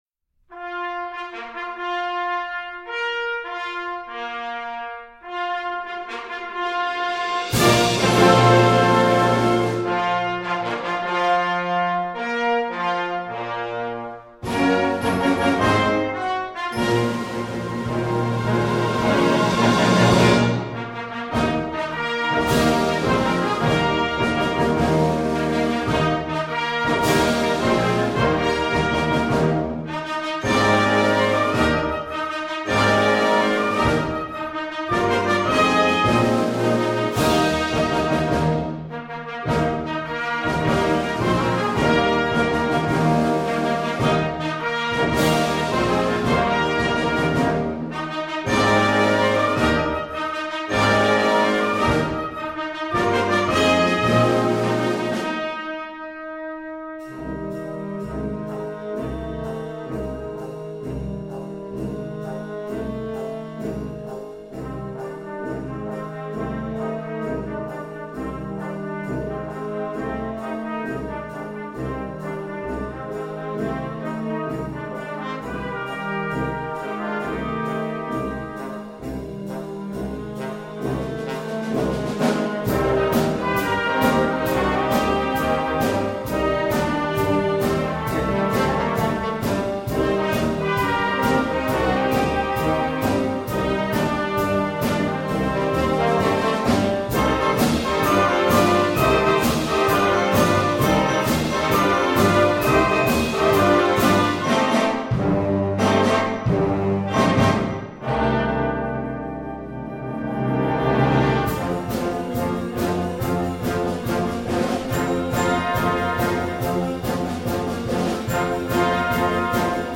Besetzung: Blasorchester
young band medley
With energy to spare, this will be a real crowd pleaser!